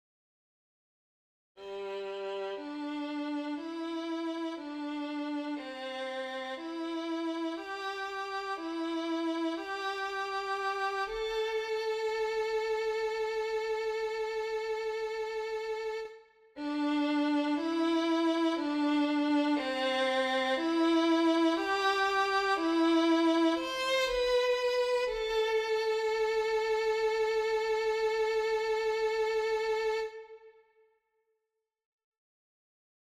Key written in: C Major